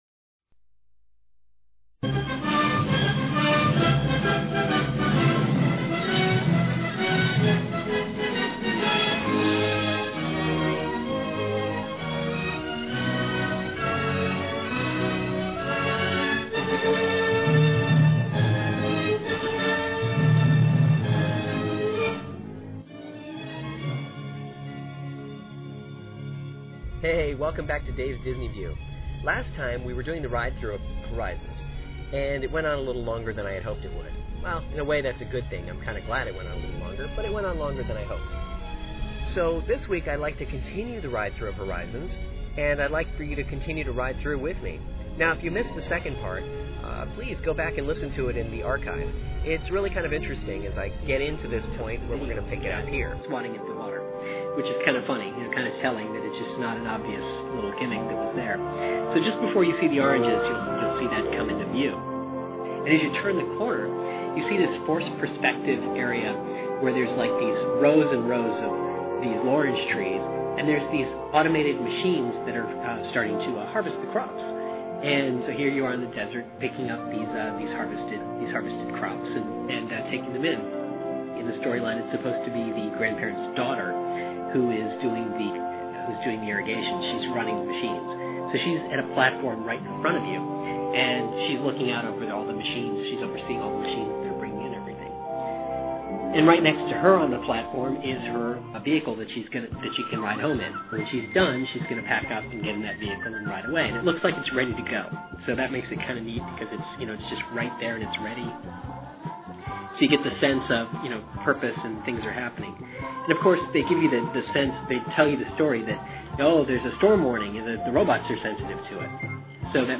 Welcome to part 2 of the ride-through the attraction that once was Horizons. I'll narrate what you "see" on the latter part of the ride, and take you through the exit of the ride, and again share some stories with you.